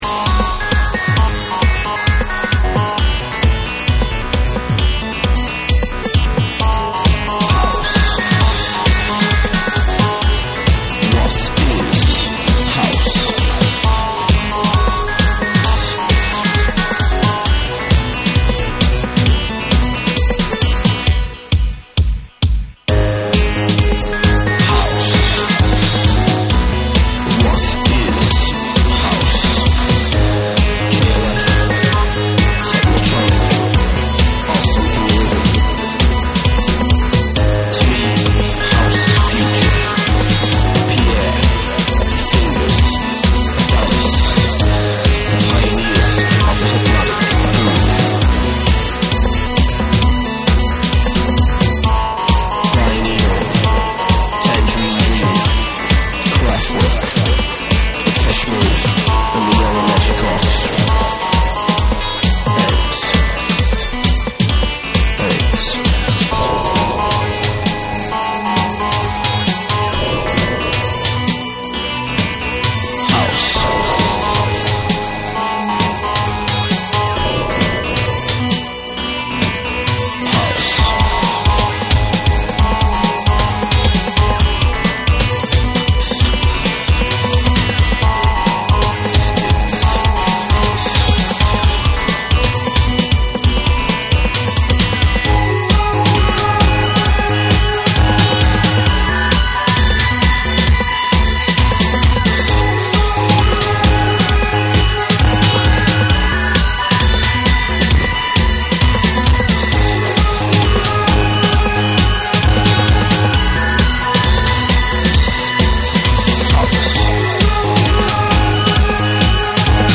TECHNO & HOUSE / BLEEP TECHNO